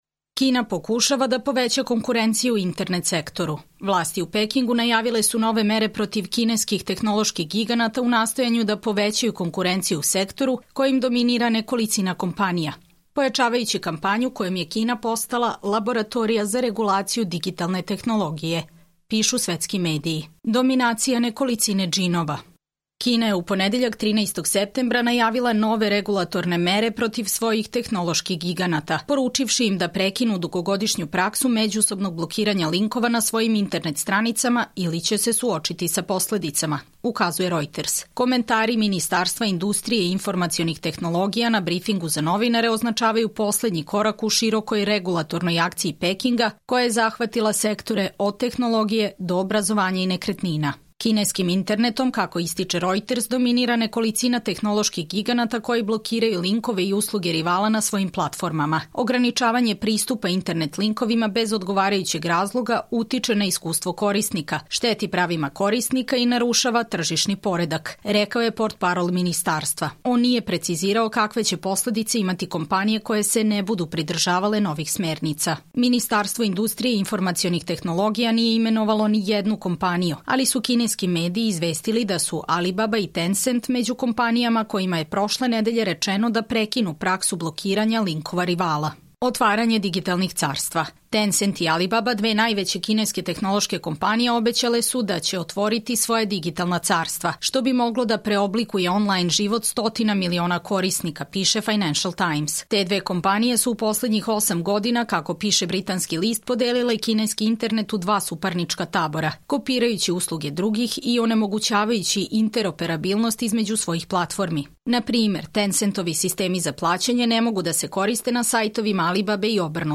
Čitamo vam